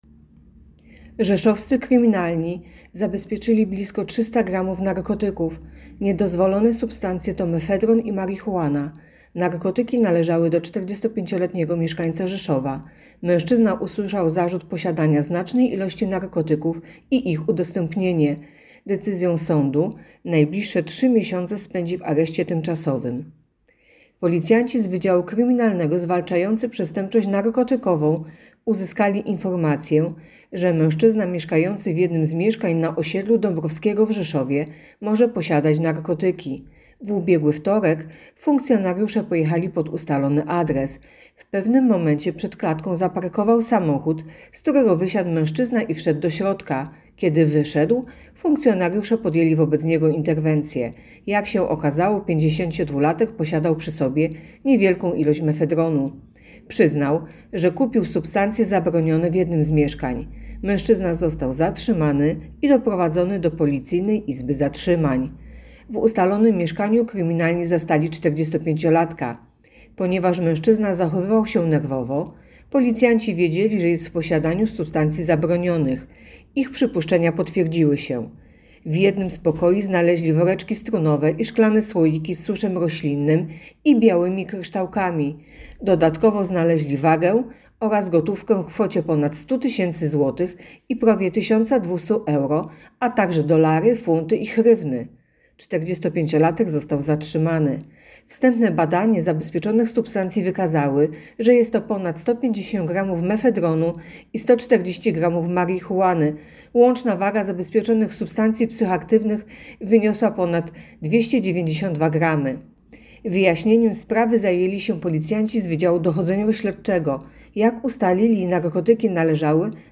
Opis nagrania: Informacja pt. Areszt dla 45-latka za posiadanie narkotyków.